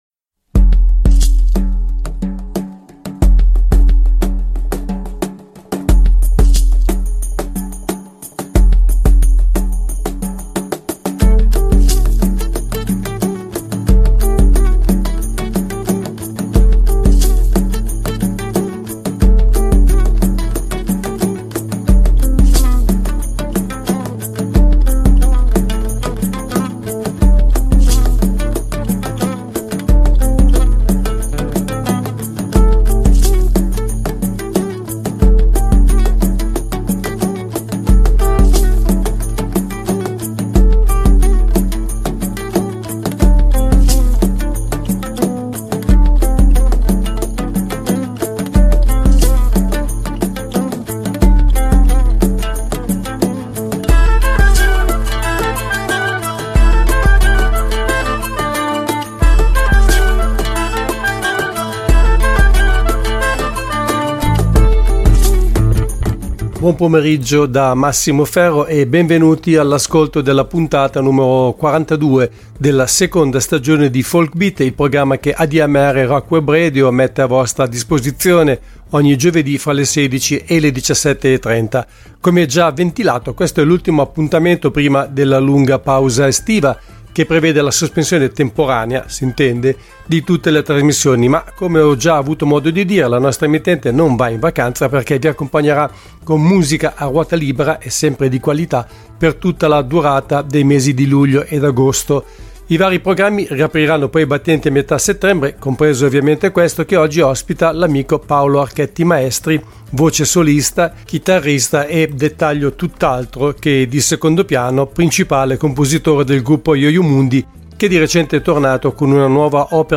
Part I: �Folk Beat� (29.06.2023) Ospite del programma al telefono